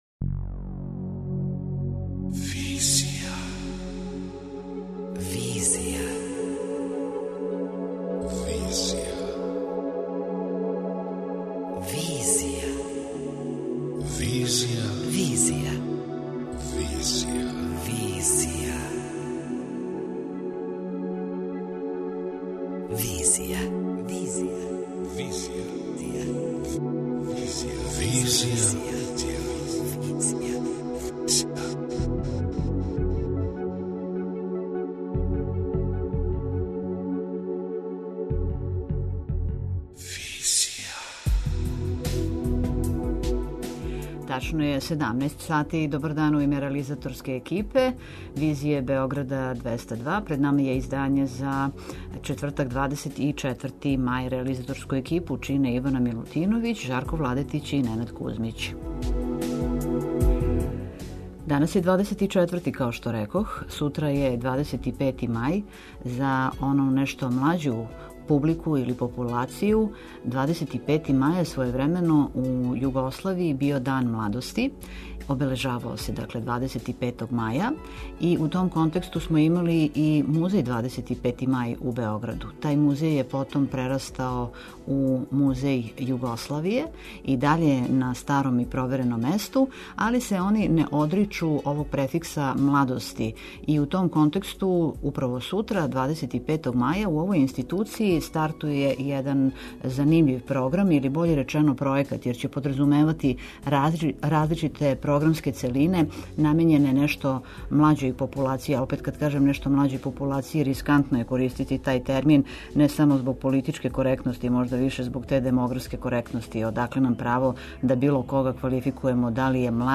преузми : 27.50 MB Визија Autor: Београд 202 Социо-културолошки магазин, који прати савремене друштвене феномене.